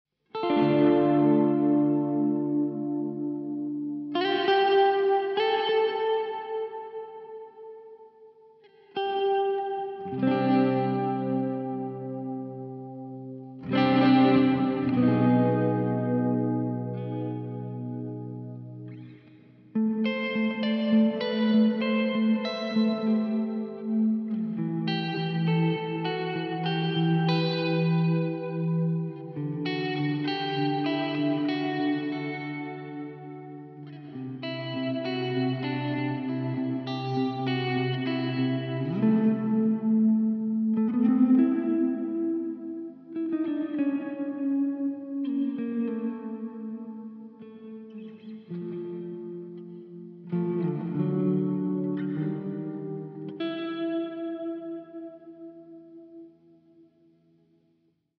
Aufgenommen wurden die folgenden Klangbeispiele an der Neck-Position einer Harley Benton CST-24. Der standardmäßig verbaute Tonabnehmer wurde durch einen Seymour Duncan SH-1 getauscht. Verstärkt wurde das Ganze mit einem Laney Ironheart Studio und einer emulierten Marshall 1960 Lead Box.
Alle Regler wurden hierfür auf die 12 Uhr Position gebracht.
Hall Reverb
Das Touch My Hall emuliert diesen Raumklang und erzeugt eine entsprechende Tiefe.
tb_audio_touchmyhall_03_hall_12_uhr.mp3